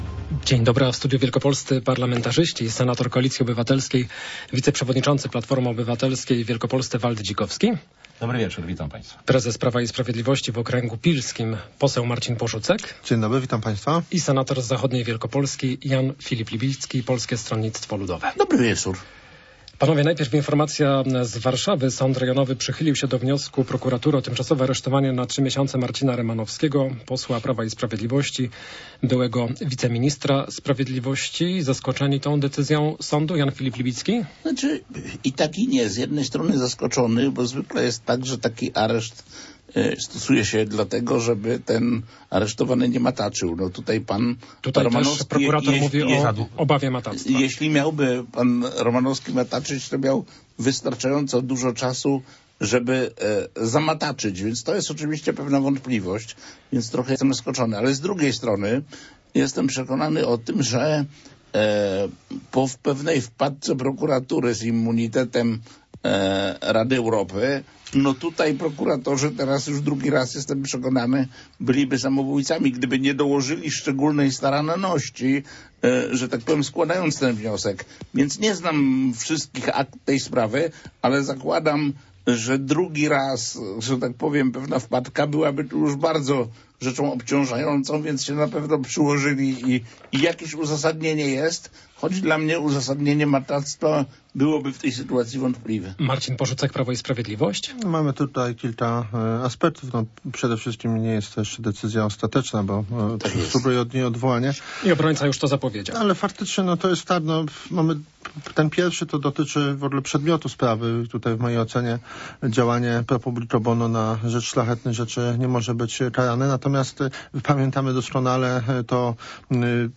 Z wielkopolskimi politykami porozmawiamy dziś o wyborach prezydenckich, budżecie państwa oraz roku rządów obecnej koalicji.